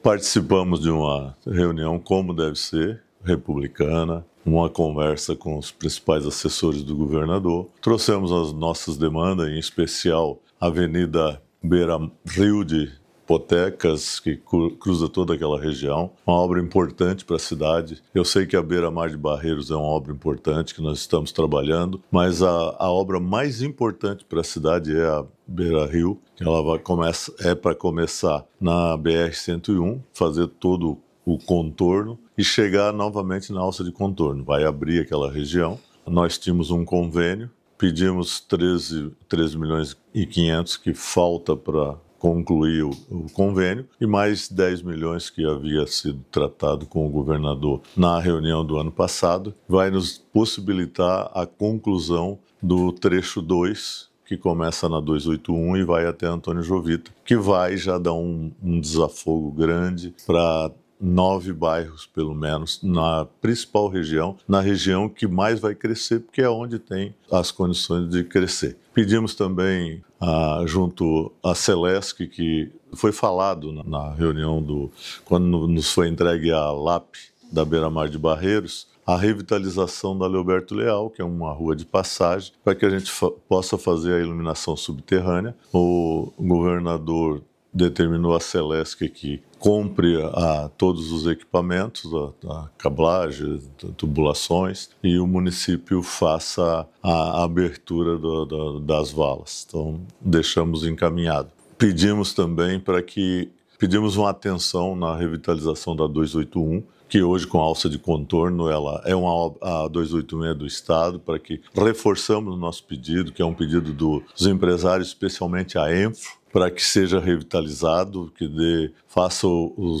O prefeito destaca obras de mobilidade e infraestrutura e faz um balanço da conversa com o governador: